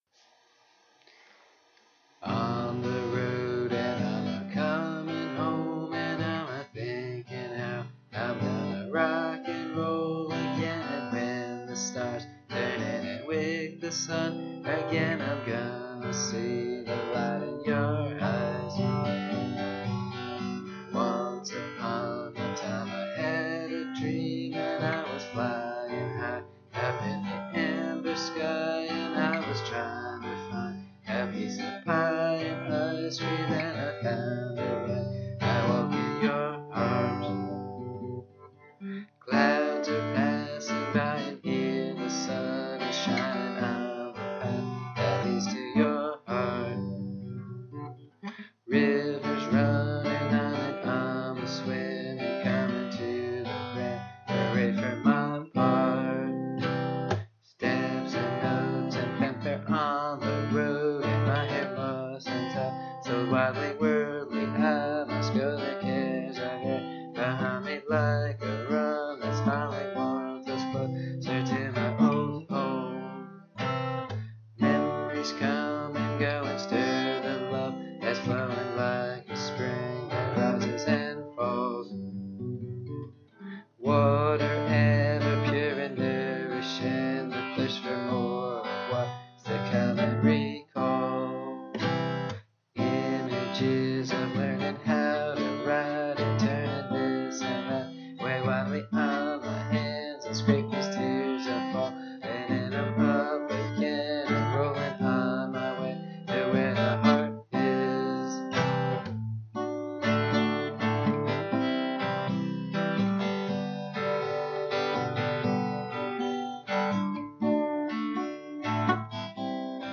The lyrics are below, and the voice and the guitar, me singing and playing, are in the link.